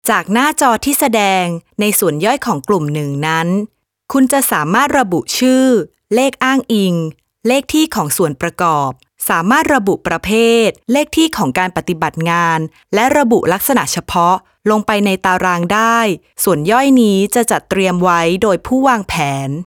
I have a home recording studio with high quality audio equipments such as Nueman TLM 103 microphone, Focusrite Class A preamp, KRK V6 studio monitor and Digidesign Mbox.
I deliver all recording with no breath no noise edited audio.
Sprechprobe: Werbung (Muttersprache):